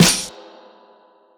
eYR_SNR.wav